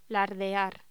Locución: Lardear